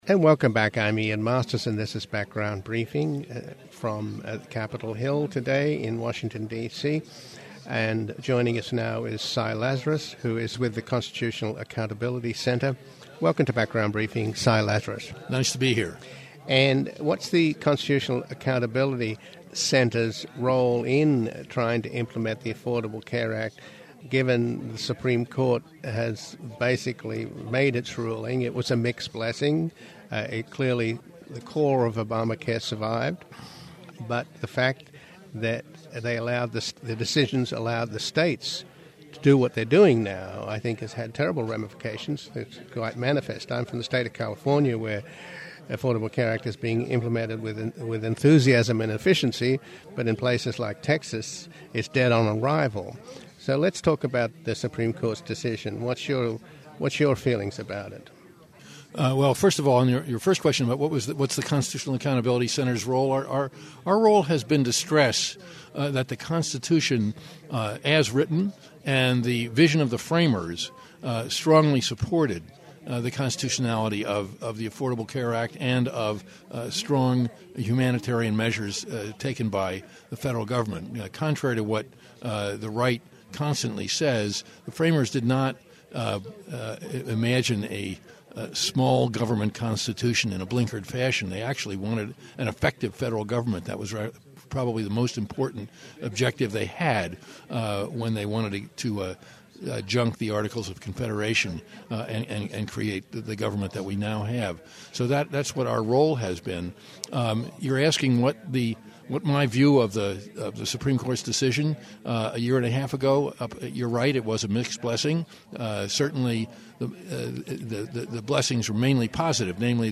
January 23 - Families USA Health Action 2014 Broadcast from Capitol Hill
Today, we examine the status and prospects for health care reform in this election year as the Affordable Care Act is implemented across the country, and some states are implementing it with enthusiasm and efficiency, while other states, thanks to the Supreme Courts decision, are refusing to expand Medicaid, thus denying millions of poor residents of their states health care coverage at no expense to the state. We are broadcasting from the Families USA Health Care Forum on Capitol Hill where advocates of health care reform and activists across the country are engaged in trying to bring health care reform to their states.